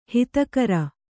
♪ hitakara